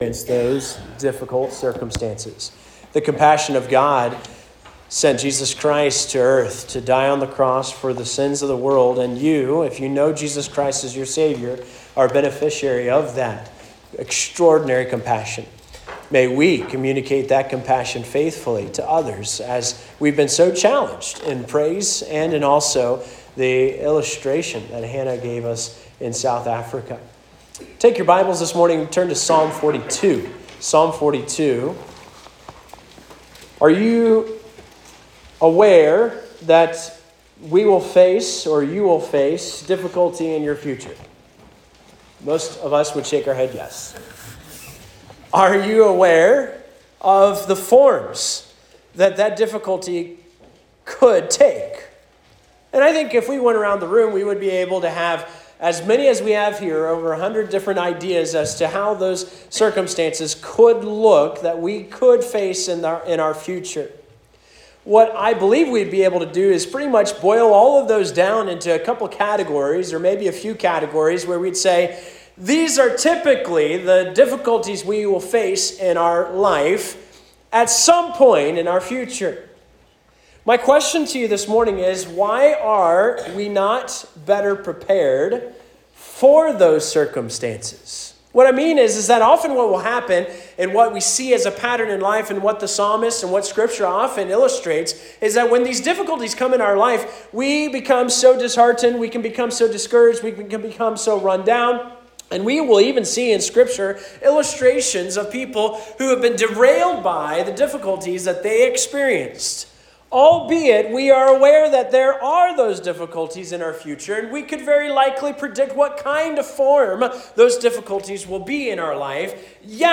The sermon discusses the importance of being prepared for difficulties in life and emphasizes the need for a healthy spiritual response system.